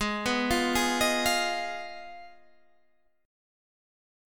G#m6 chord